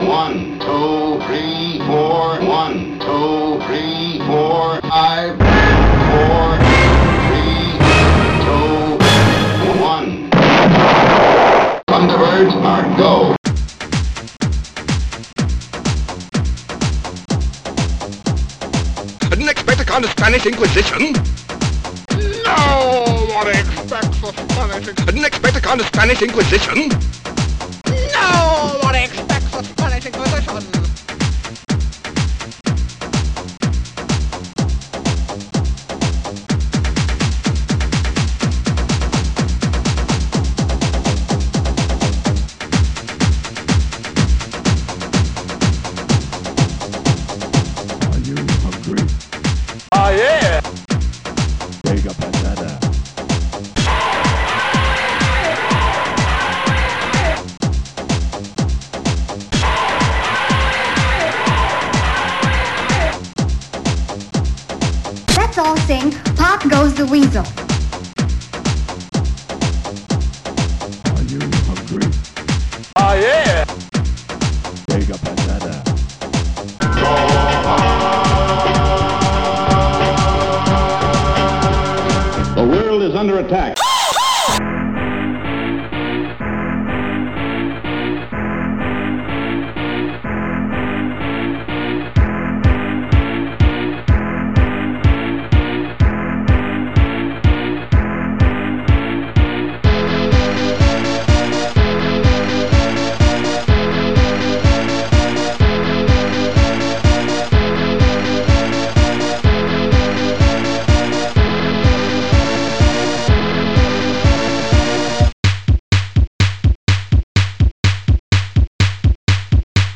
drums no.1
explosion!!!!
shouts
HEAVY BASS DRUM!!!!